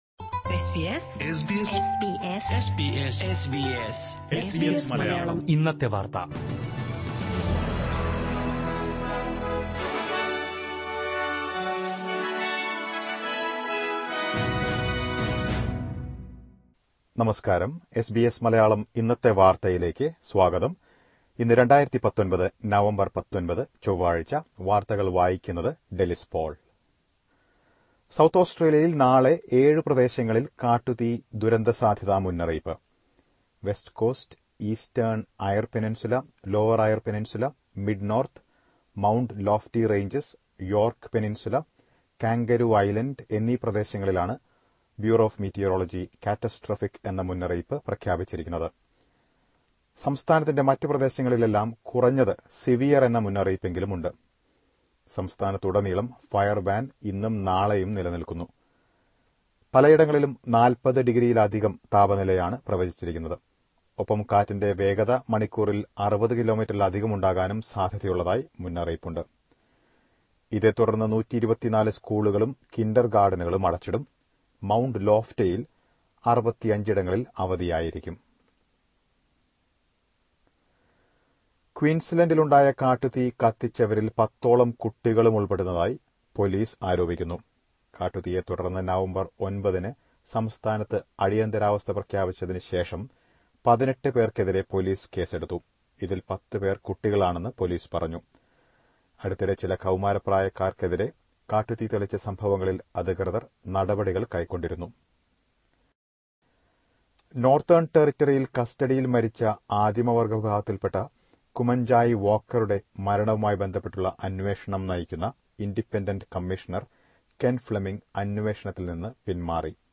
2019 നവംബർ 19ലെ ഓസ്ട്രേലിയയിലെ ഏറ്റവും പ്രധാന വാർത്തകൾ കേൾക്കാം…